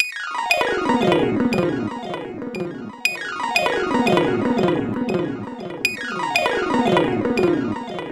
Gliss 2fer 4 Gliss.wav